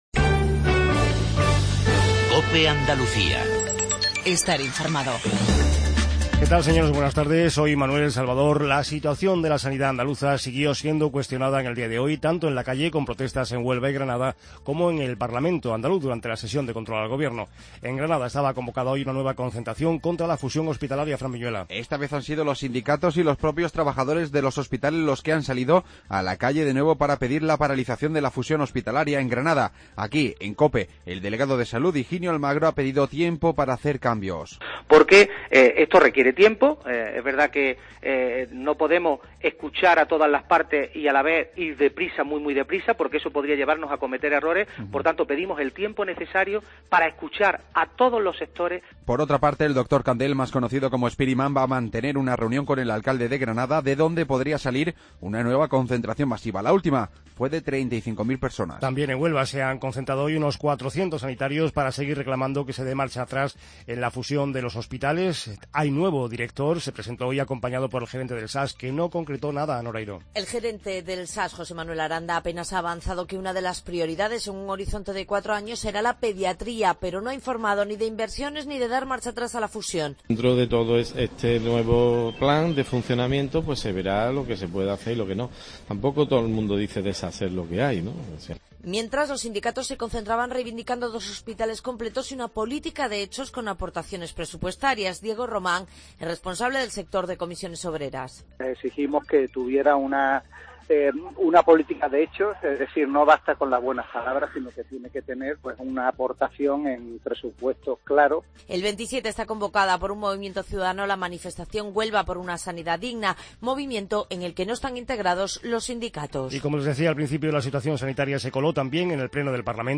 INFORMATIVO REGIONAL MEDIODIA